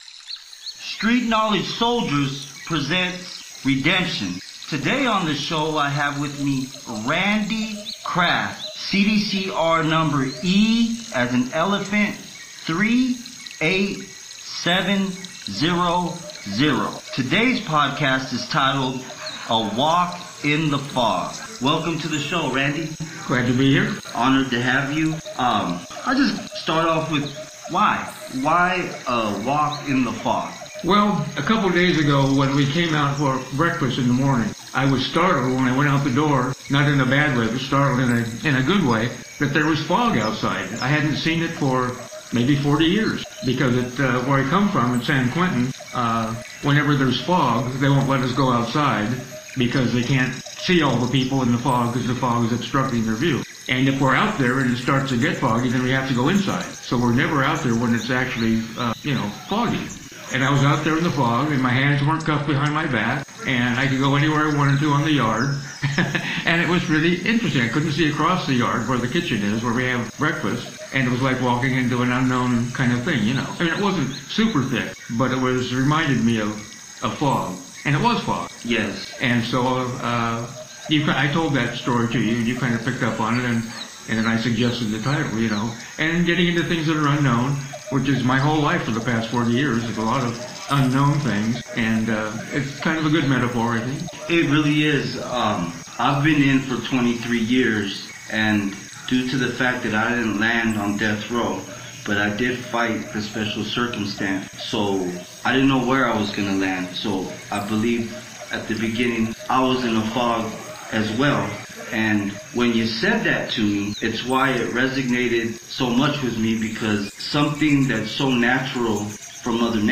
A WALK IN THE FOG, IS THE FIRST CONSENTED INTERVIEW GIVEN BY RANDY KRAFT , A CALIFORNIA DEATHROW INMATE.